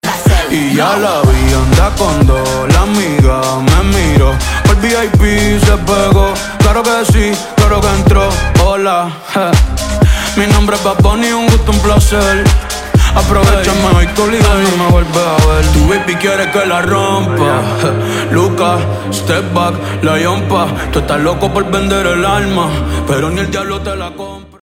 Tema musical